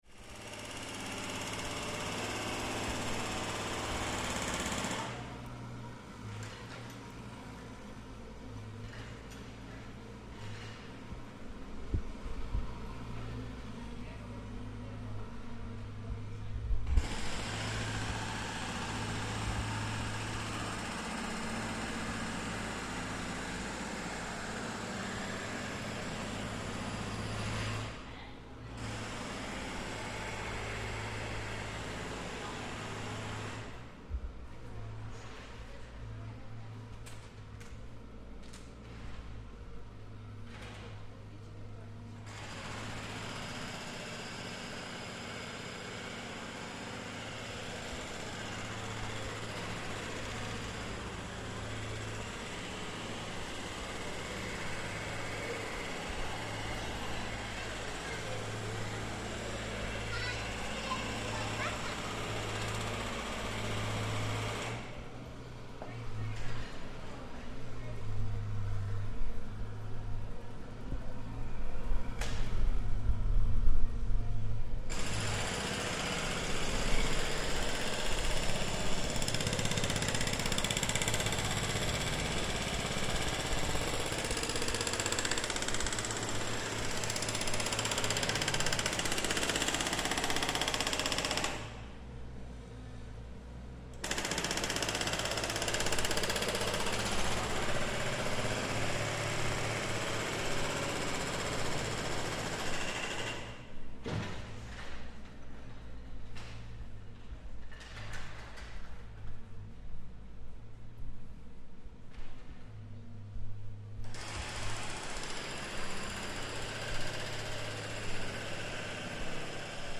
Roadworks and drilling interrupt the day
Loud industrial drilling from roadworks in the centre of Treviso, Italy. The sound reverberates around the historic city centre, spreading for huge distances - here we record a close-up walkpast of the roadworks in action.